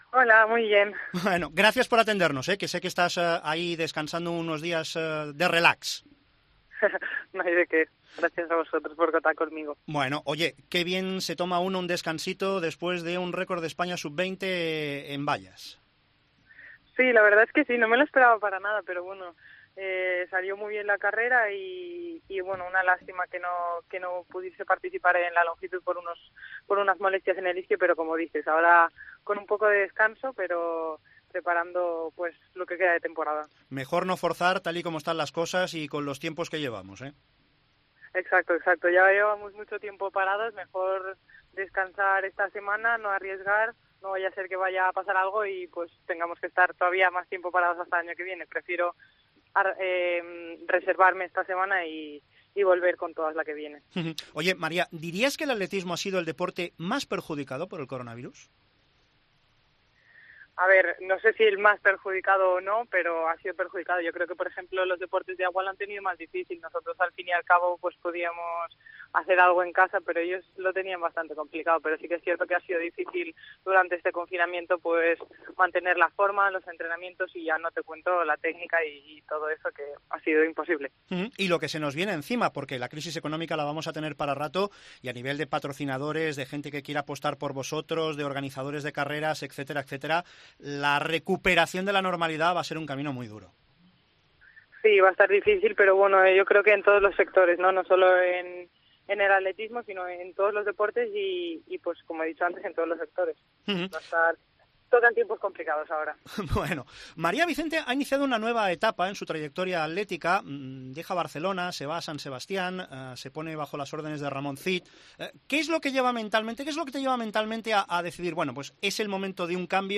Entrevista a María Vicente en Esports COPE